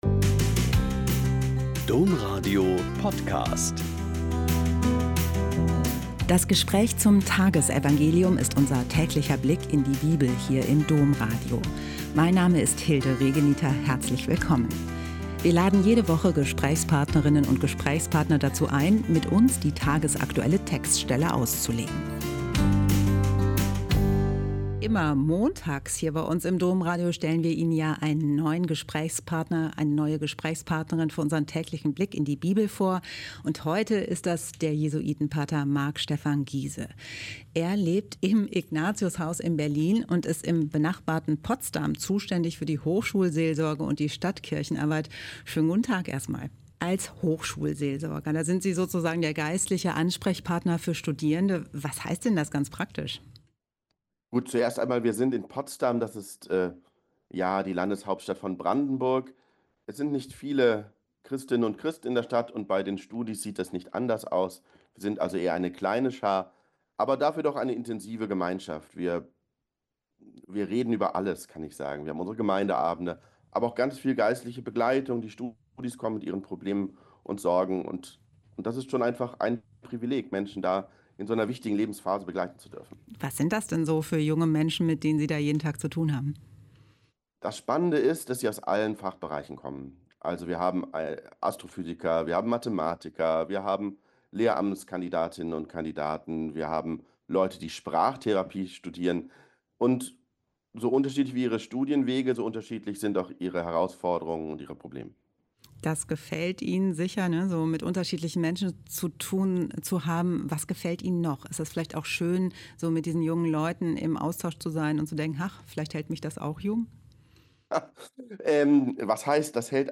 Joh 15,26 – 16,4a - Gespräch